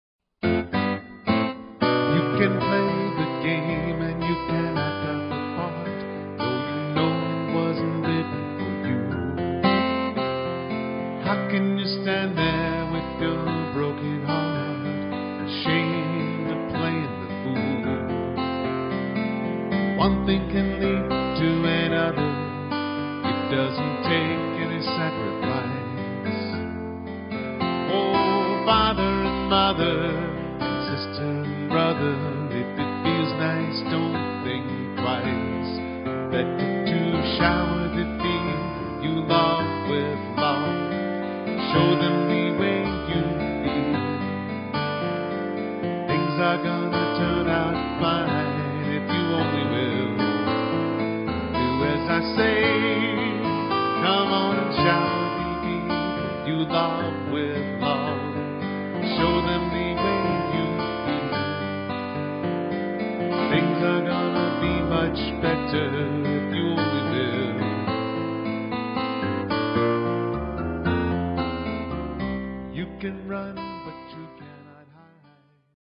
Pop/Rock